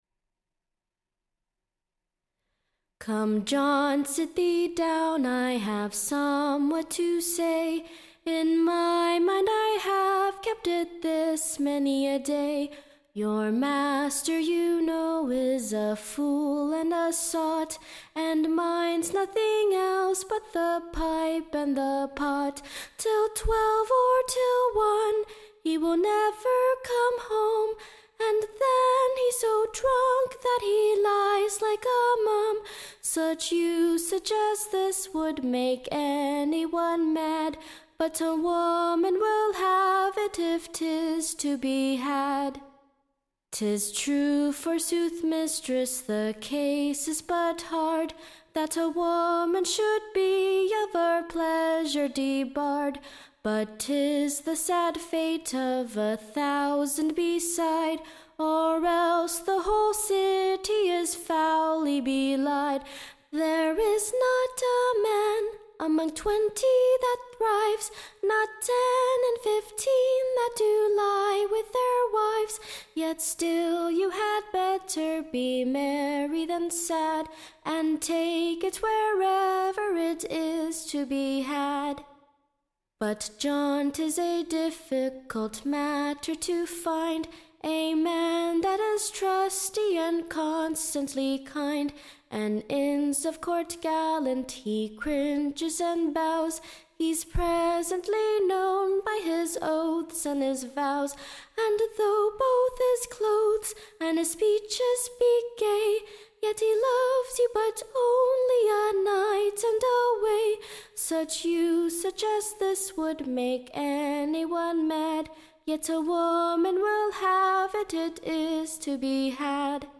EBBA 35290 - UCSB English Broadside Ballad Archive
Tune Imprint To the Tune of, Packington's pound, or, What should a young woman, &c. or, Captain Digby.